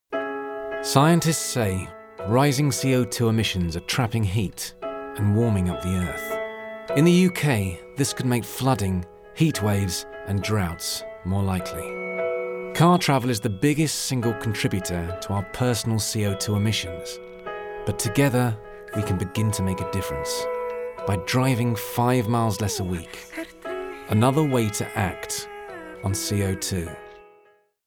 Erklärvideos
Warm, voll und ohne regionalen Akzent. Er arbeitet von seinem Heimstudio aus und verwendet nur die beste Ausrüstung.
Ich verwende ein Sennheiser MKH-416-Mikrofon, Audacity, Focusrite Scarlett Solo und habe ein komplett ausgestattetes Aufnahmestudio.